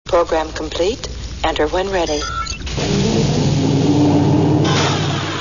Star Trek Sounds
Englisch Holodeck Computer